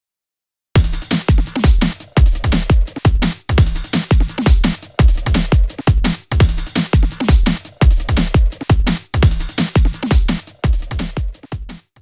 Geist2 Factory/Preset/Kits/Drum kits/DnB1
ドラムンベースのオーソドックスなリズムパターン。
Drum & BassのLoop/Beats/REXからBeats 03を使用。
2小節分のリズムパターンが完成。こんな感じ。